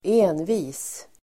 Uttal: [²'e:nvi:s]